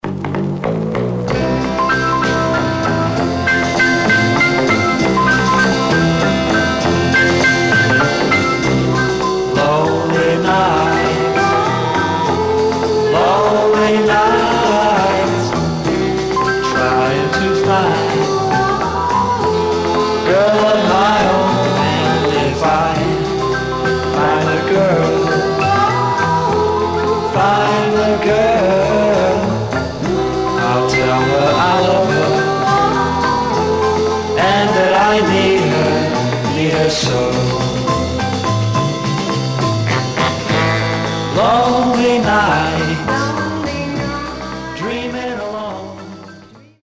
Condition Surface noise/wear Stereo/mono Mono
Teen